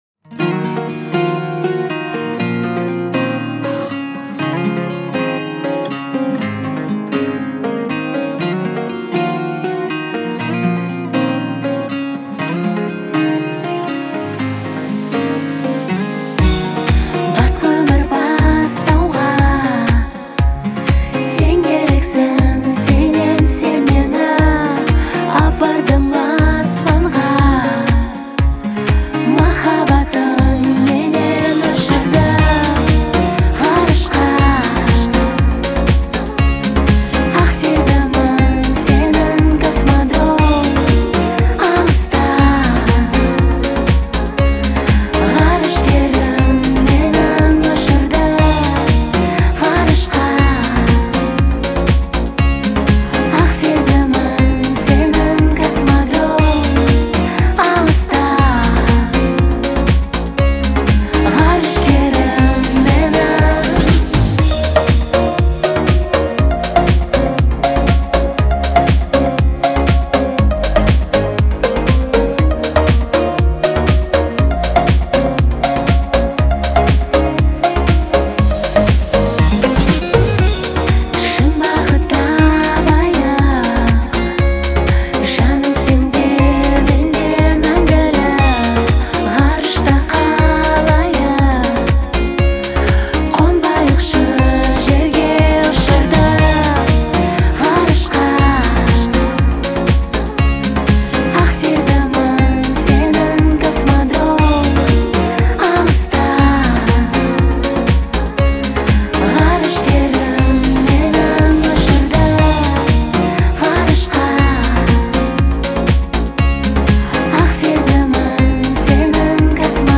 это трогательная песня в жанре поп